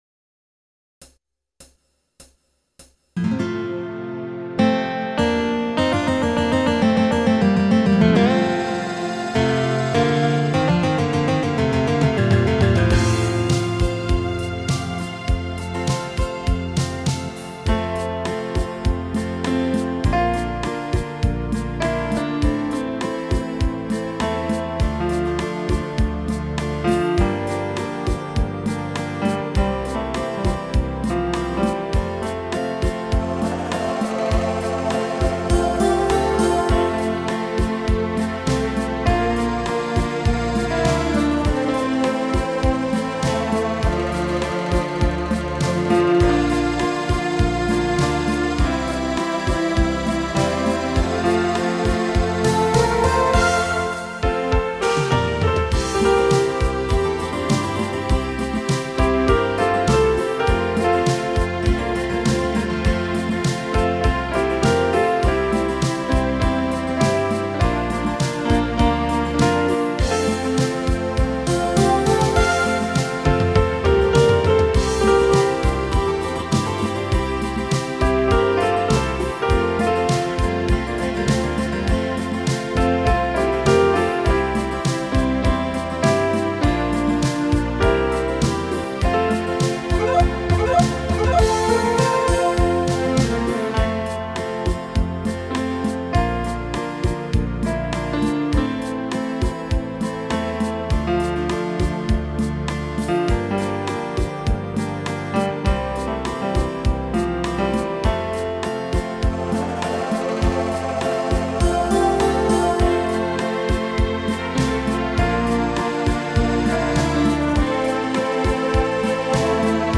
5/   Nestárnúce slovenské melódie
upravené pre hru na piano, ukážka